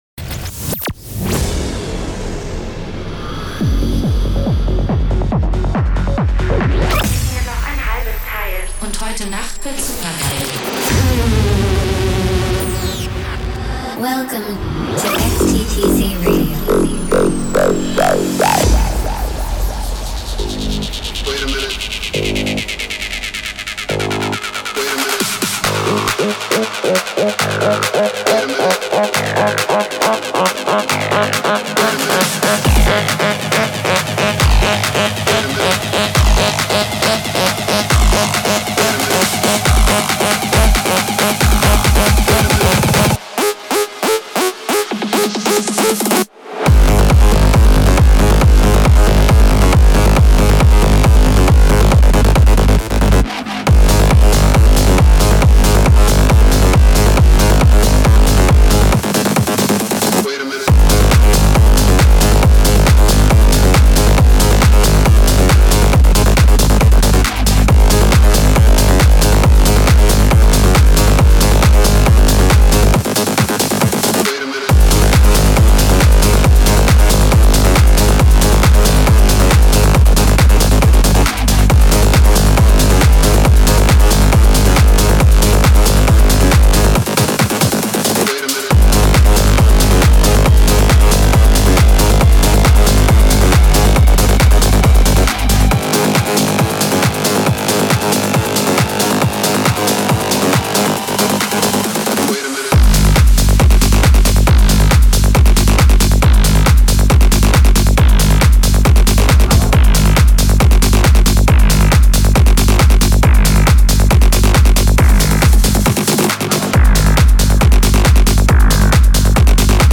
music DJ Mix in MP3 format
Genre: Techno